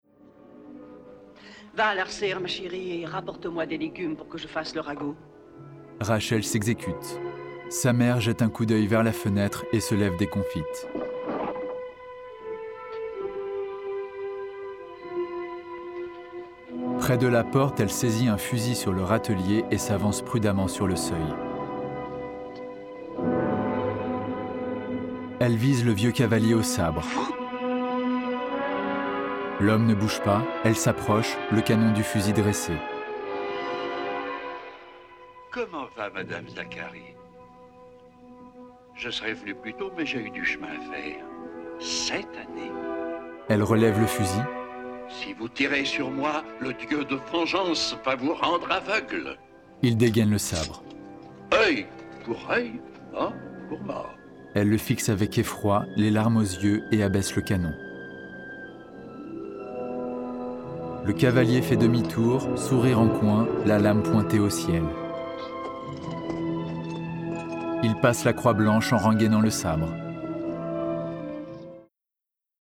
- Baryton